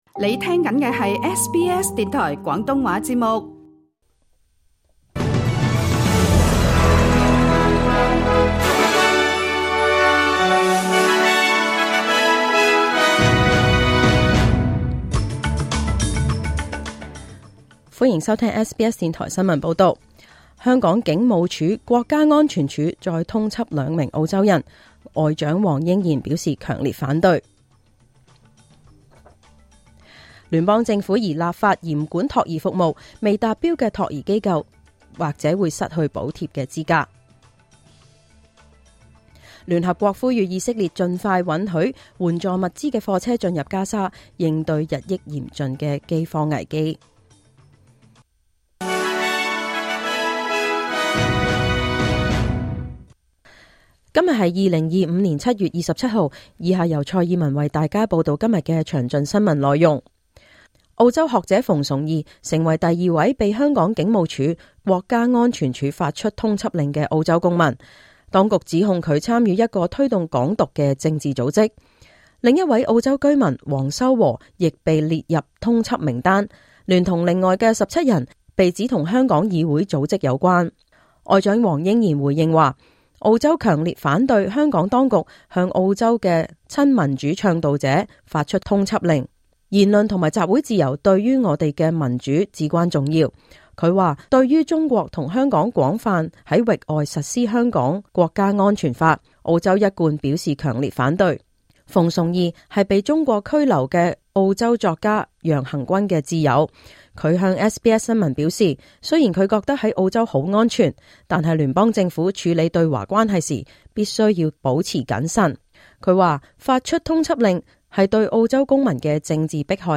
2025 年 7 月 27 日 SBS 廣東話節目詳盡早晨新聞報道。